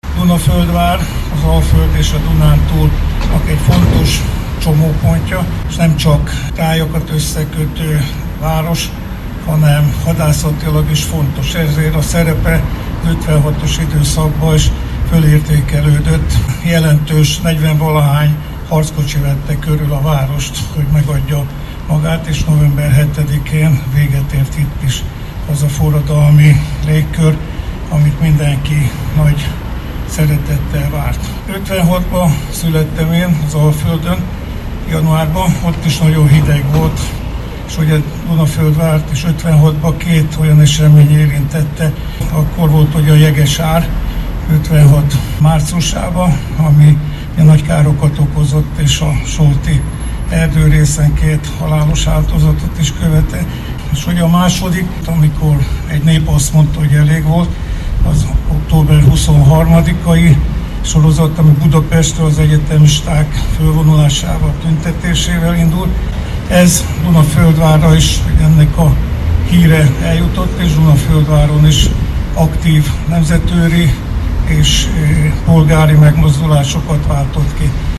Dunaföldváron a műsor után megkoszorúzták a Városháza falán az 56-os emléktáblát a jelenlévők. Az ünnepséget Süli János országgyűlési képviselő is megtisztelte jelenlétével, aki a koszorúzás előtt így beszélt.